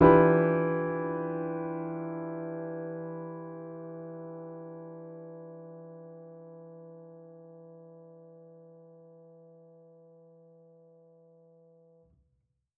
Index of /musicradar/jazz-keys-samples/Chord Hits/Acoustic Piano 1
JK_AcPiano1_Chord-Em7b9.wav